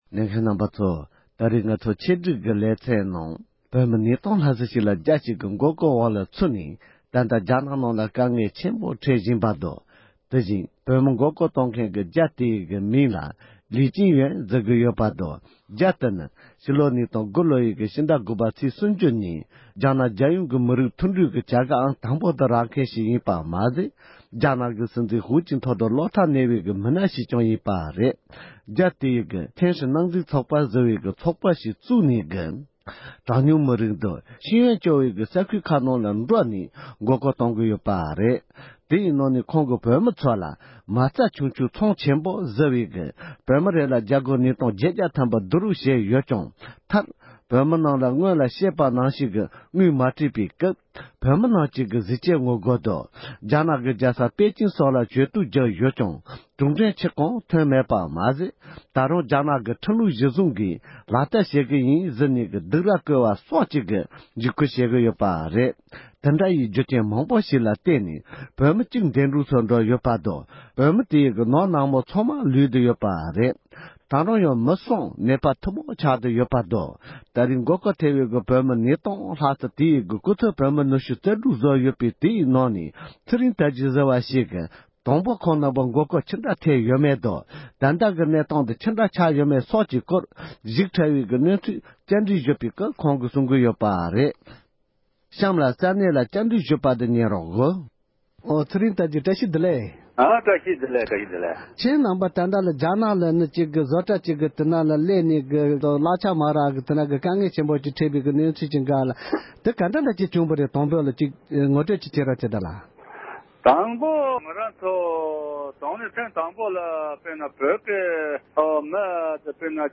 འབྲེལ་ཡོད་མི་སྣ་ལ་བཀའ་འདྲི་ཞུས་པ་ཞིག